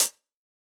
UHH_ElectroHatA_Hit-19.wav